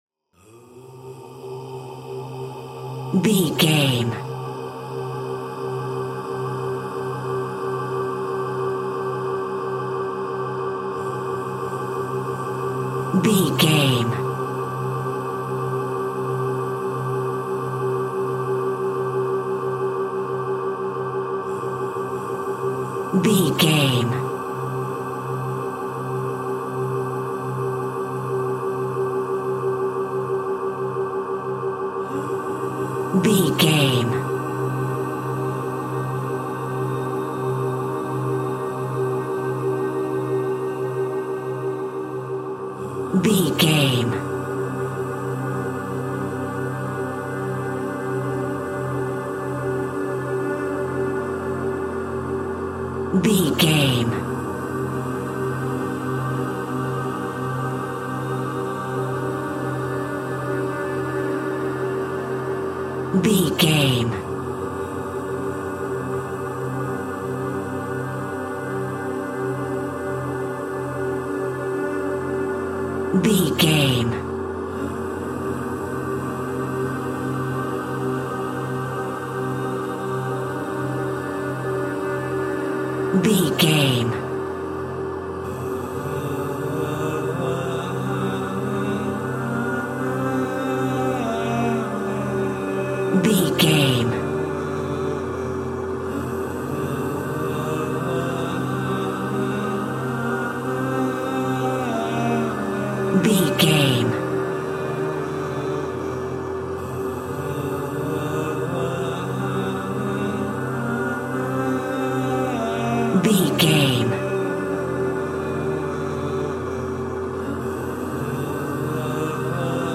Aeolian/Minor
groovy